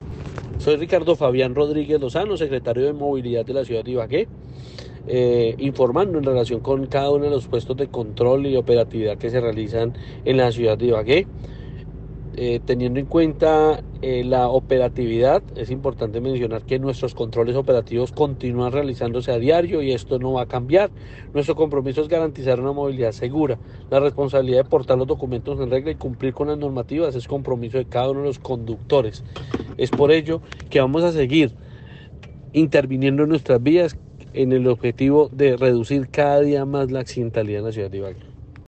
Ricardo-Rodriguez-Secretario-de-Movilidad-Puestos-de-control.-.mp3